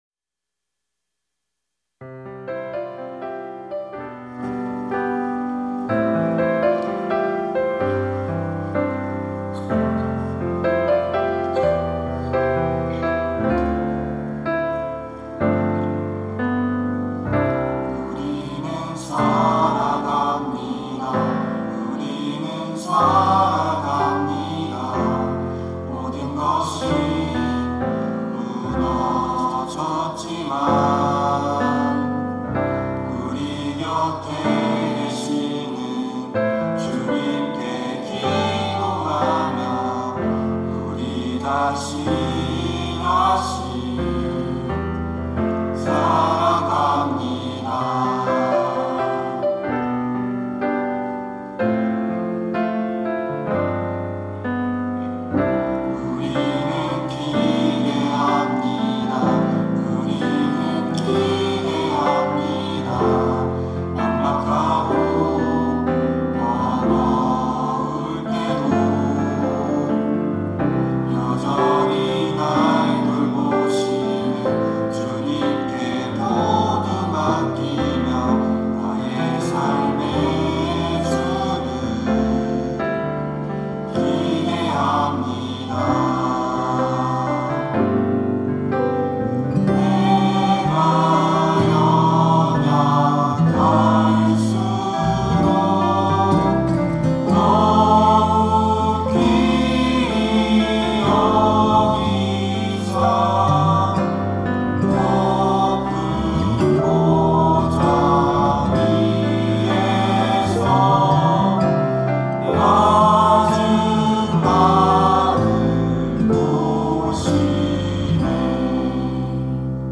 특송과 특주 - 우리는 살아갑니다
청년부 교사, 간사